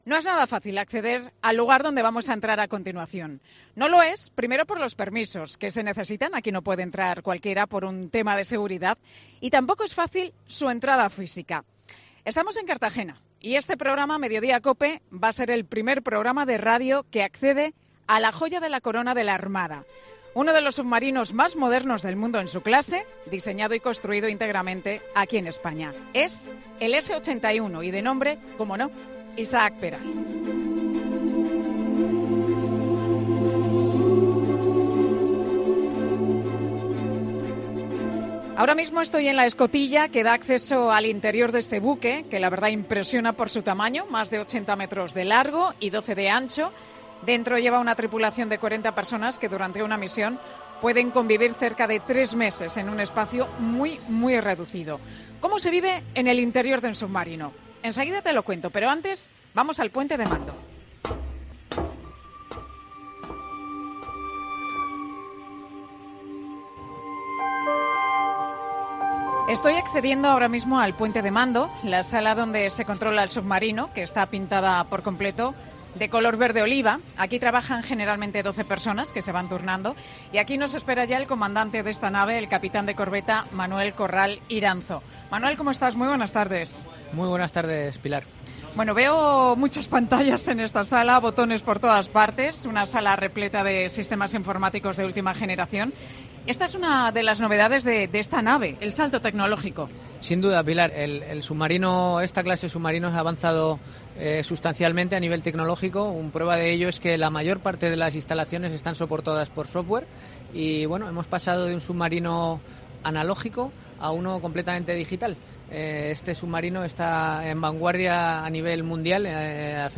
'Mediodía COPE' visita el submarino S-81 Isaac Peral de la Armada española